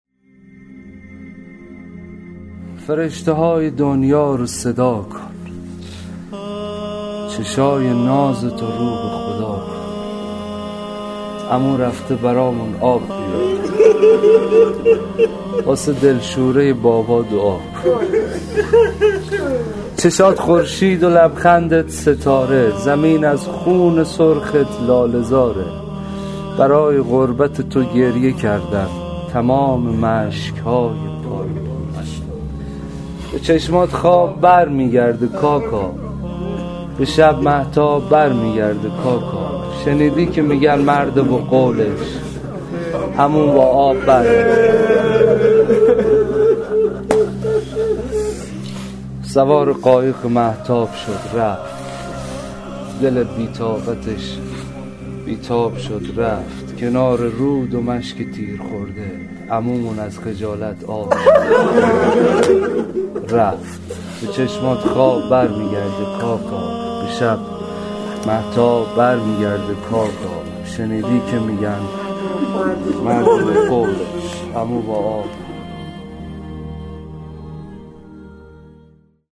صوت شعر خوانی شاعران آیینی در ایام محرم